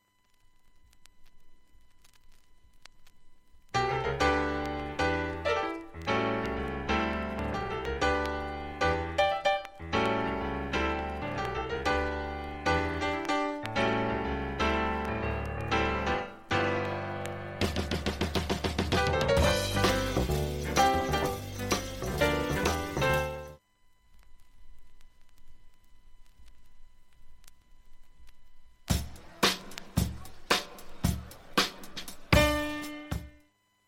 音質目安にどうぞ
ドラムブレイクではじまる